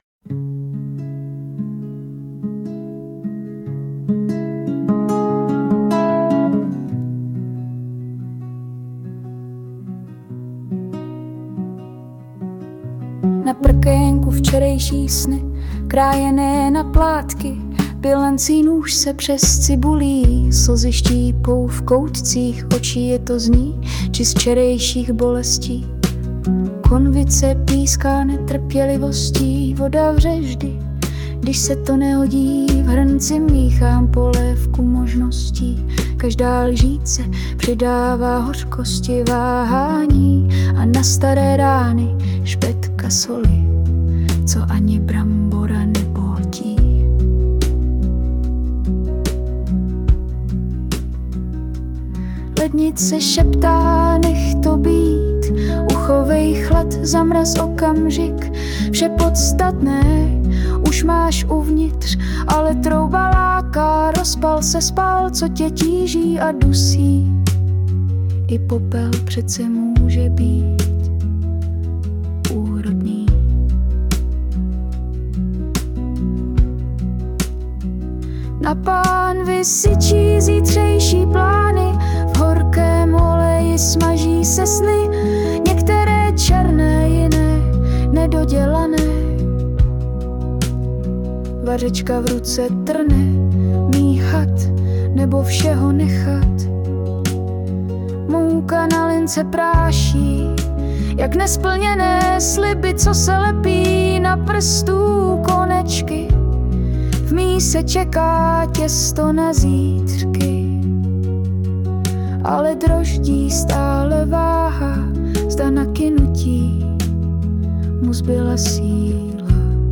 Všechno úplně ladí jak zpěv tak i napsané.
dala tomu zase sílu vnitřní promluvy - zvláštní punc a srdeční kus
prostě sílu niterného blues :)**